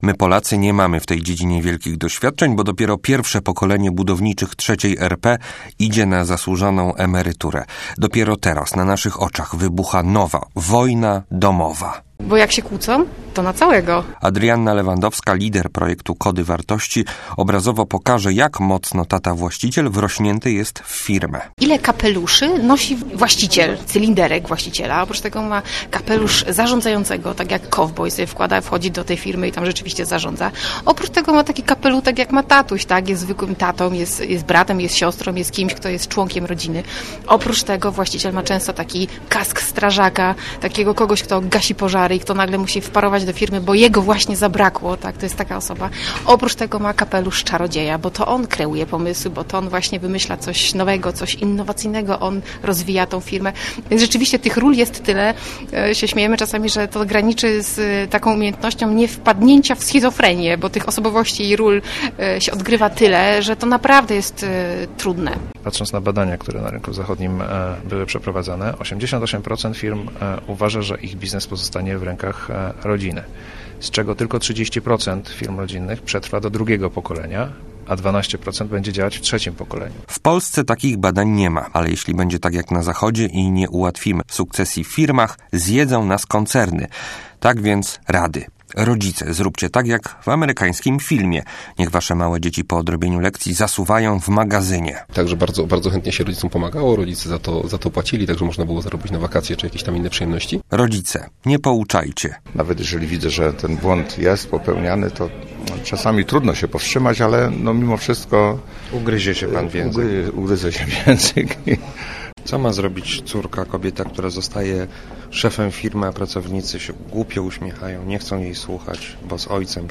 O tym jak przekazać synowi lub córce własną firmę i nie zwariować - rozmawiają właściciele firm i ich dzieci w Wyższej Szkole Bankowej w Poznaniu. W Polsce jest 600 tysięcy firm rodzinnych.